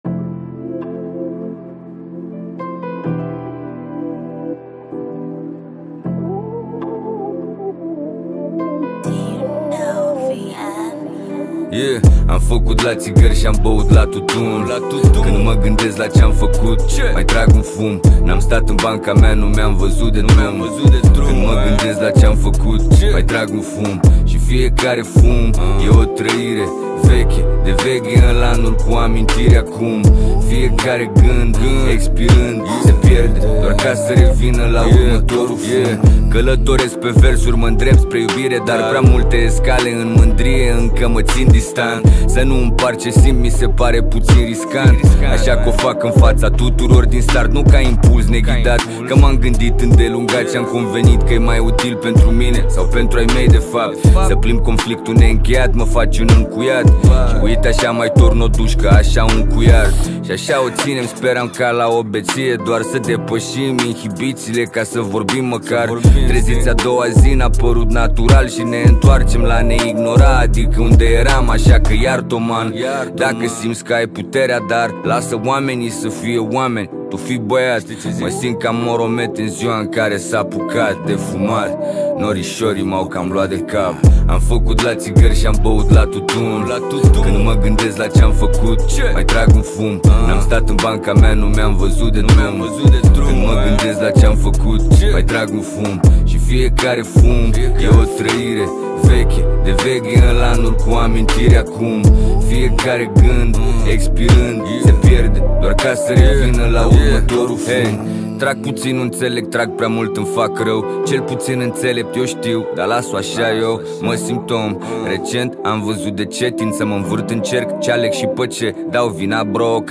Categoria: Hip Hop New